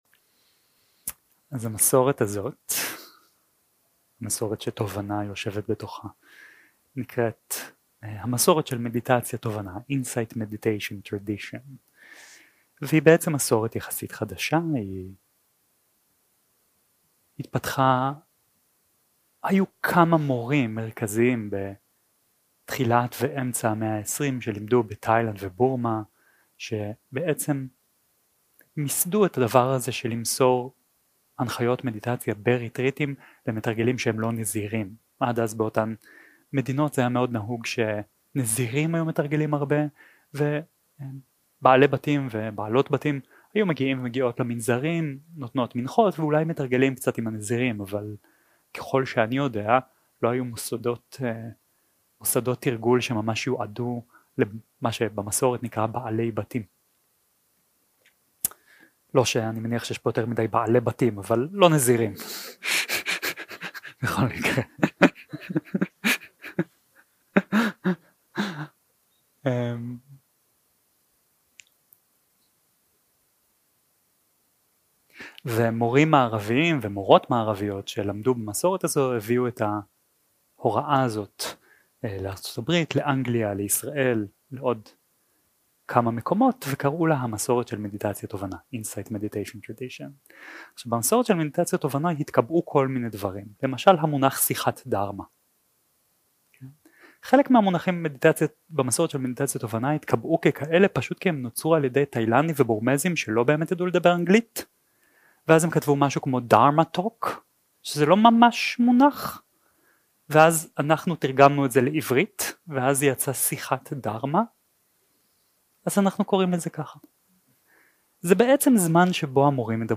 Dharma type: Dharma Talks שפת ההקלטה